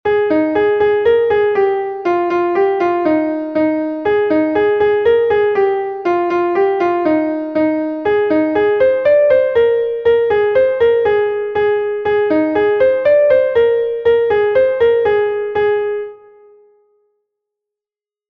Gavotenn Bro-Bourled II est un Gavotte de Bretagne